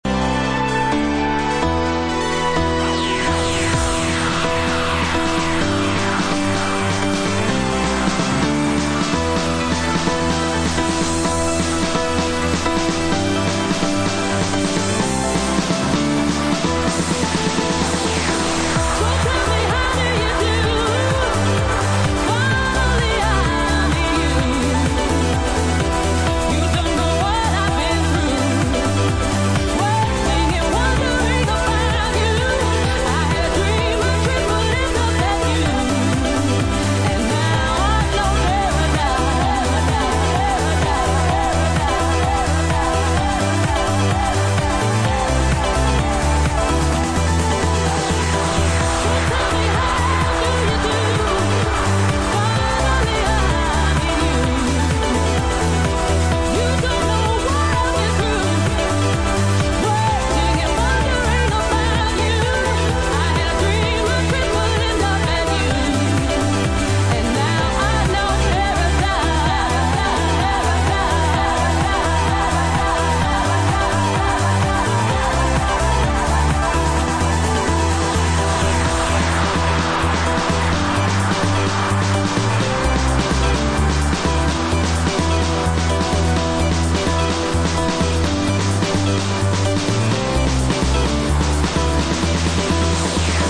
UK Gatrunek: Electronic Styl: House